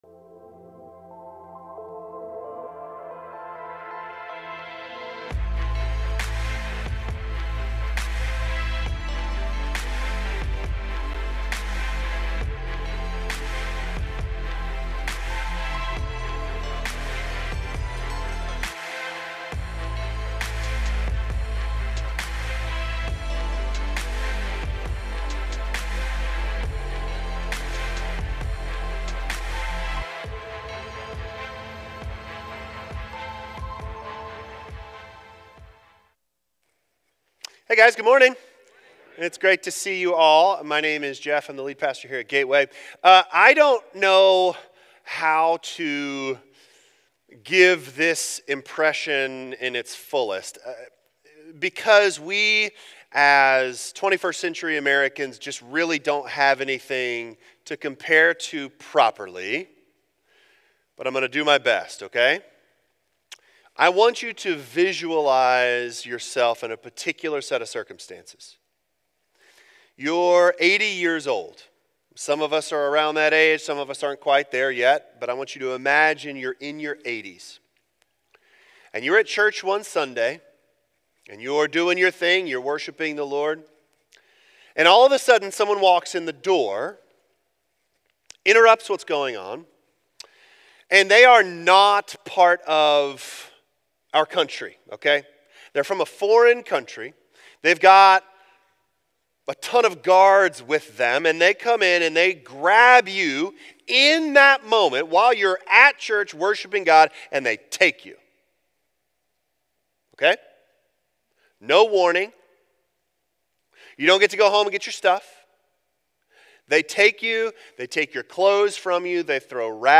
Your-Jesus-Isnt-Scary-Enough-Sermon-4.19.26.m4a